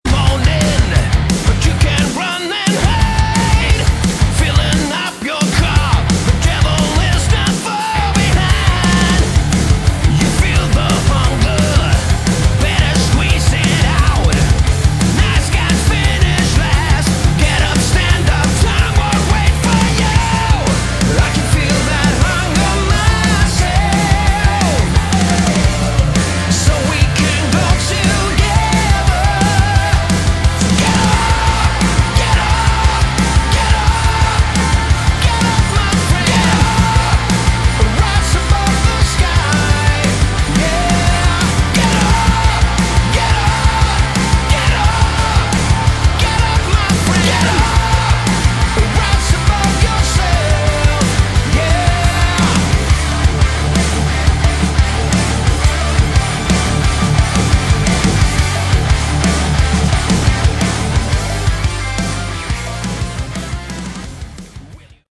Category: Melodic Rock
Vocals, Bass
Drums
Keyboards
Guitars